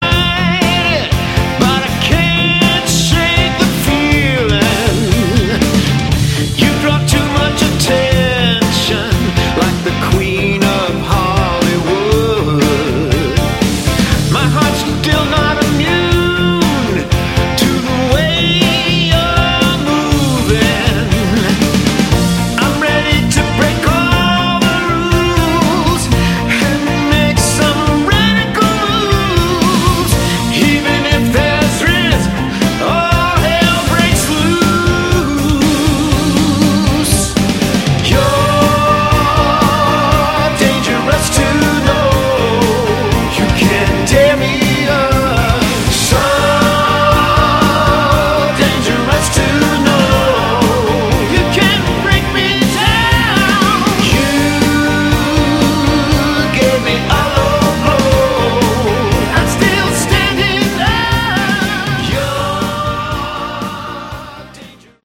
Melodic Rock